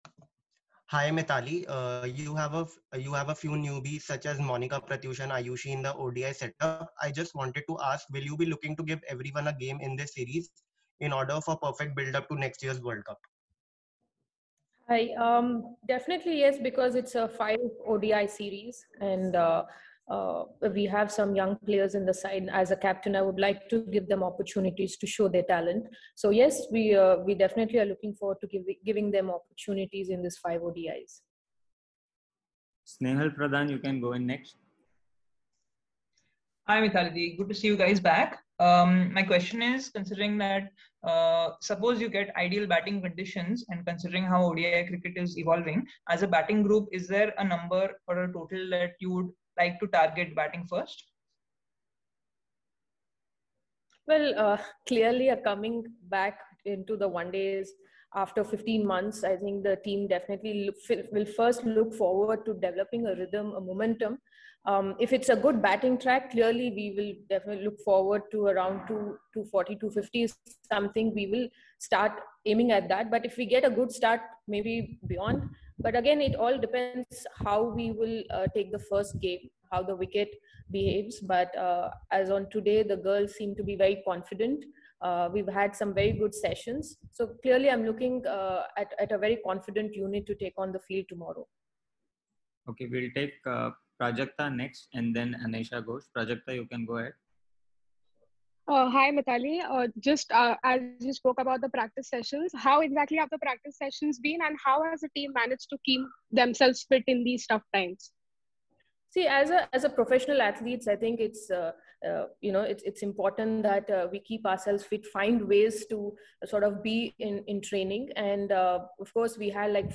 Mithali Raj, Captain of the Indian Women’s ODI Team addressed a virtual press conference today before the 1st Paytm ODI against South Africa at Lucknow.